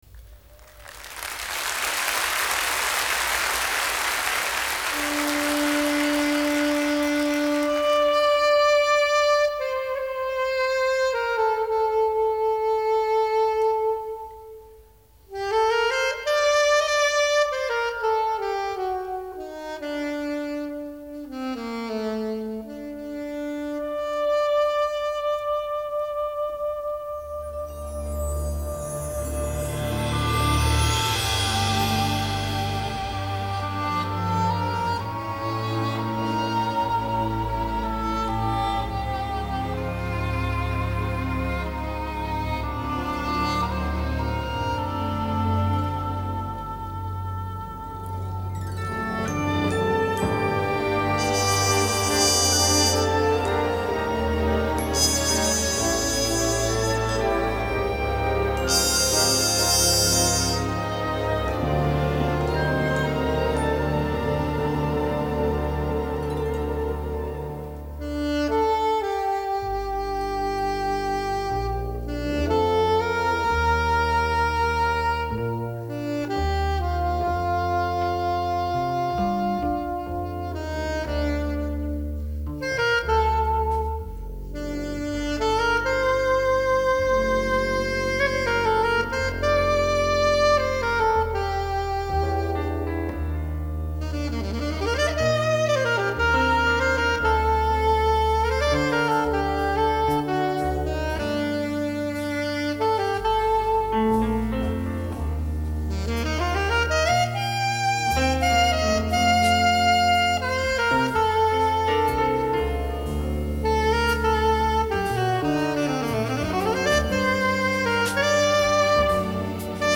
for Alto Saxophone and Symphony Orchestra